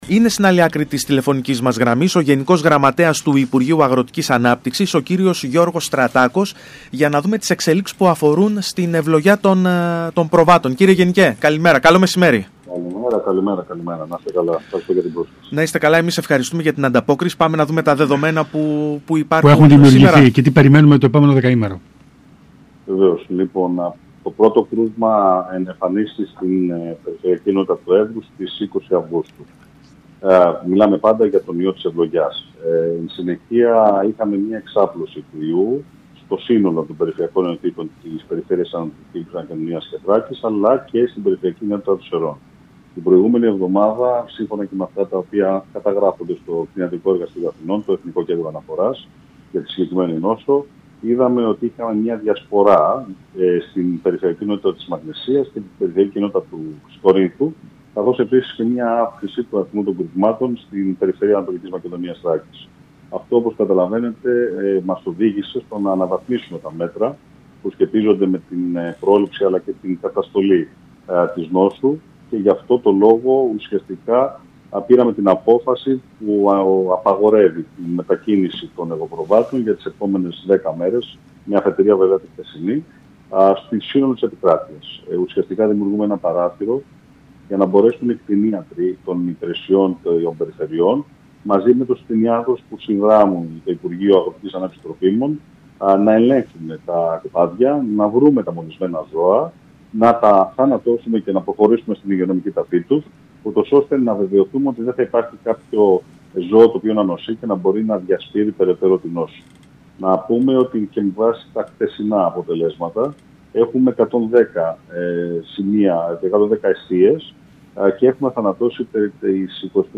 Ακούστε εδώ όσα είπε ο γενικός γραμματέας του υπουργείου Αγροτικής Ανάπτυξης Γιώργος Στρατάκος στο ΣΚΑΙ Κρήτης 92.1: